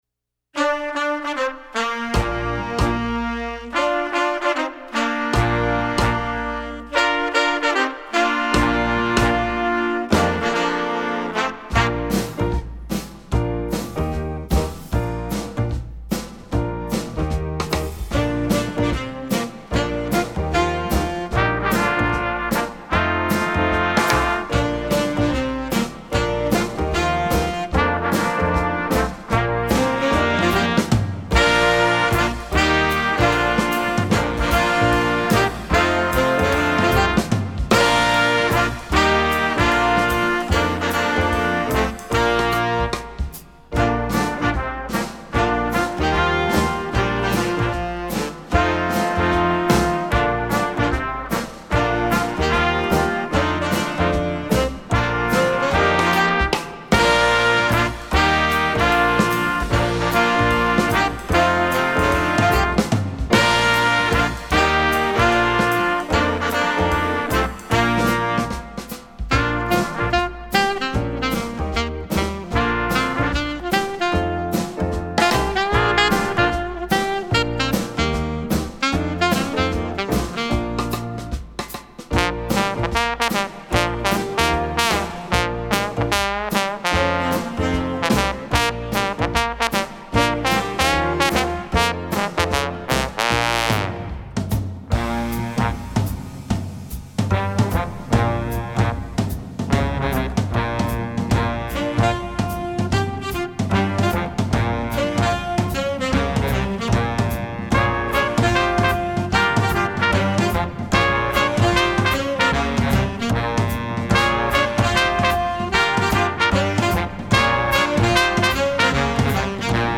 Instrumentation: jazz band
jazz, traditional